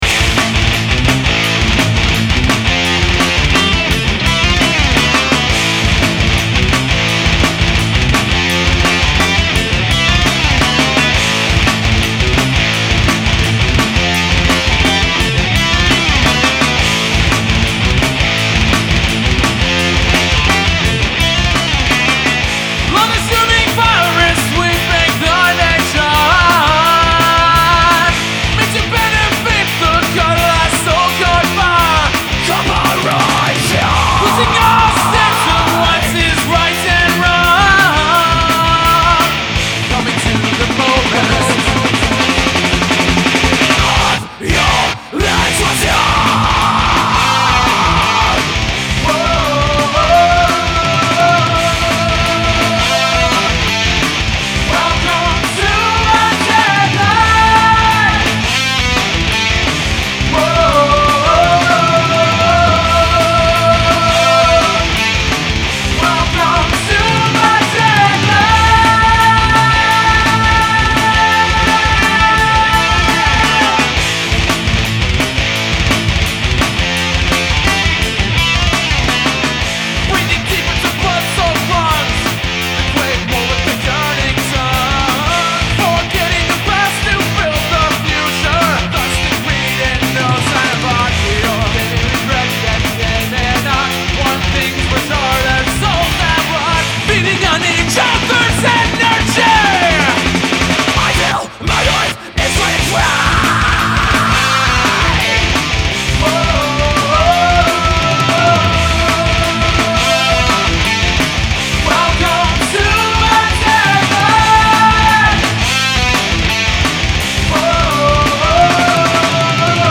I've also attached demo's of two songs that will appear on this album as well as some imagery that we've associated ourselves with in the past.
Metal, Progressive Metal, Thrash Metal, Hard Rock